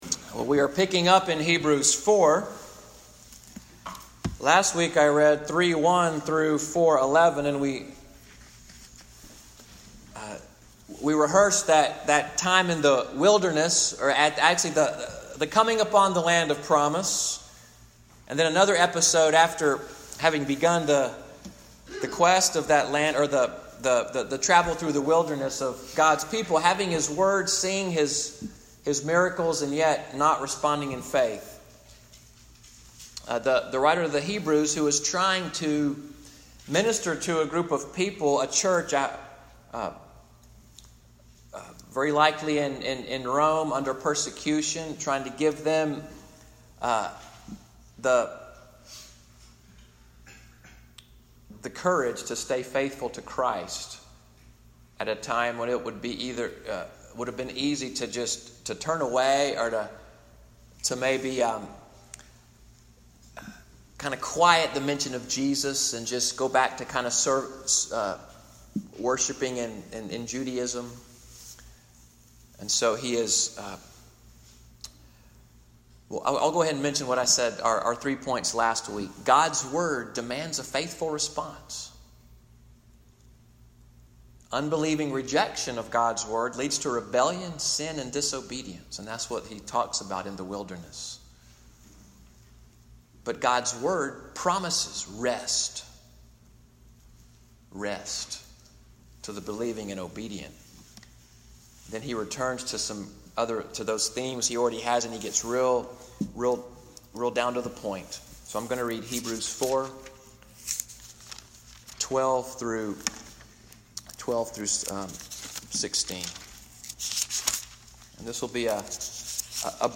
Sermon Audio from the Worship Service of Little Sandy Ridge Presbyterian Church of Fort Deposit, Alabama.